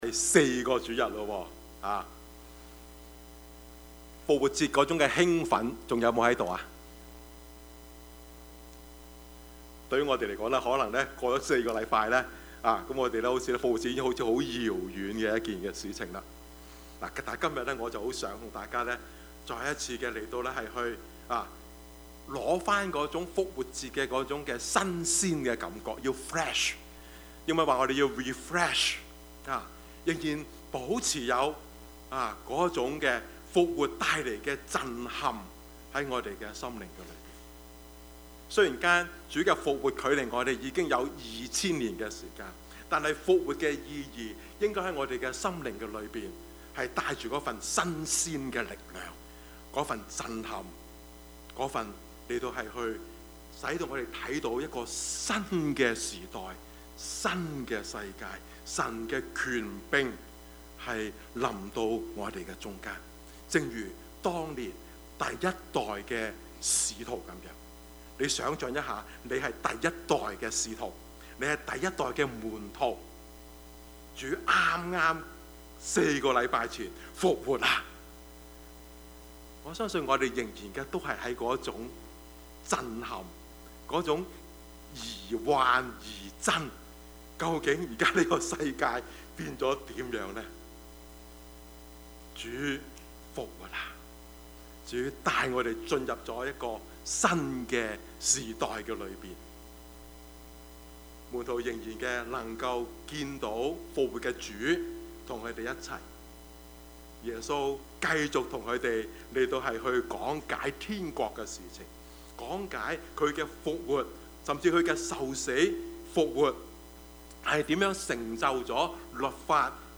Service Type: 主日崇拜
Topics: 主日證道 « 耶穌的死 你們要彼此相愛 »